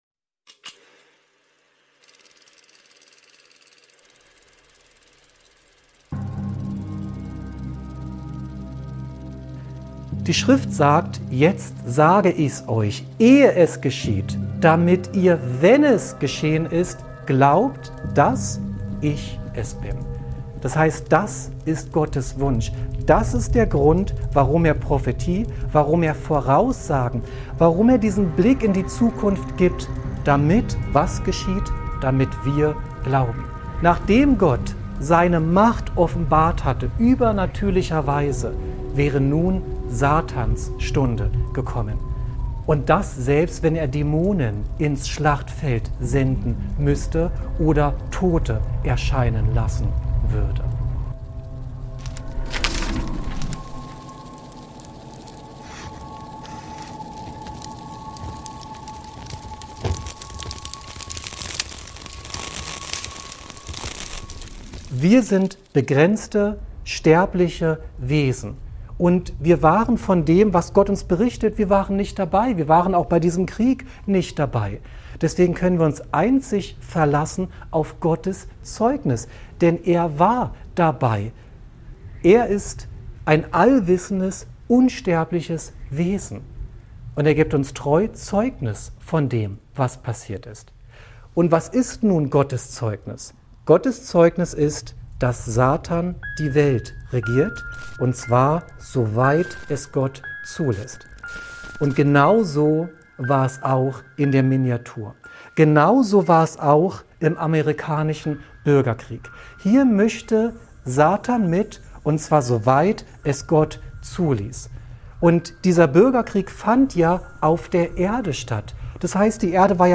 Im Vortrag wird die Verbindung zwischen Gottes Prophetie und dem Einfluss Satans auf die Welt erklärt. Es wird erörtert, wie Dämonen sich als verstorbene Generäle ausgeben und die Entscheidungen von Autoritäten manipulieren.